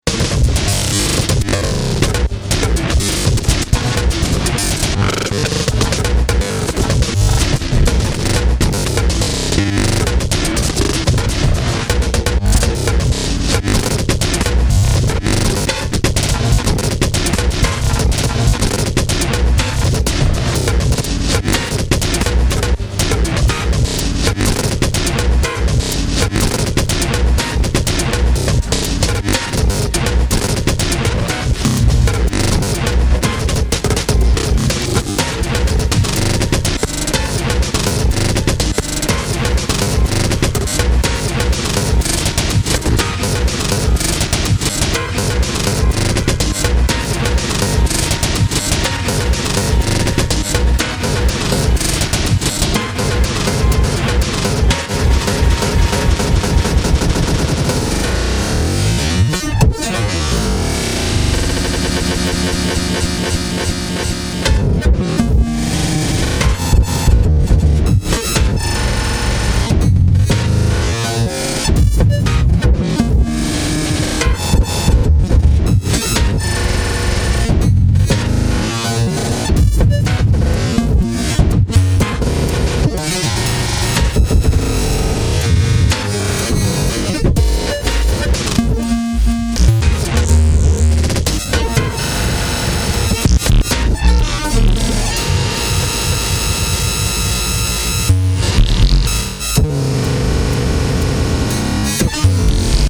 構築の逆のような八つ裂きの音がPANされてて、脳みそをつんざきますよ。